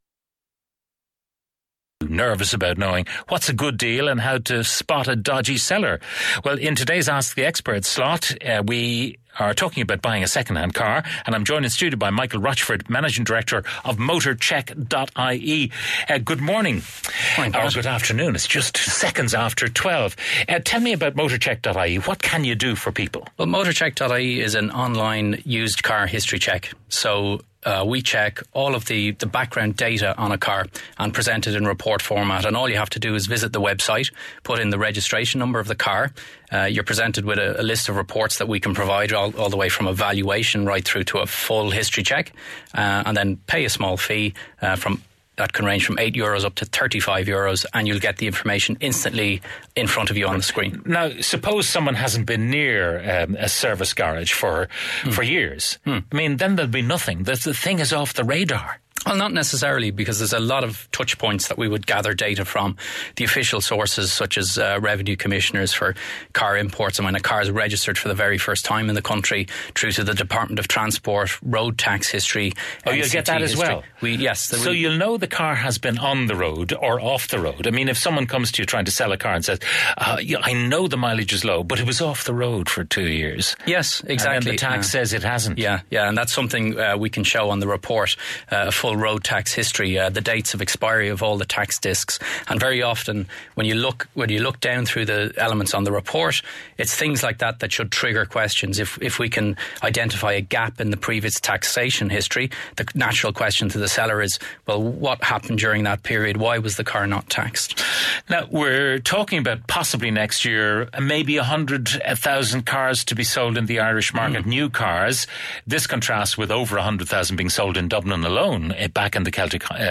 chats with Pat Kenny on Newstalk about what to look out for when buying a used car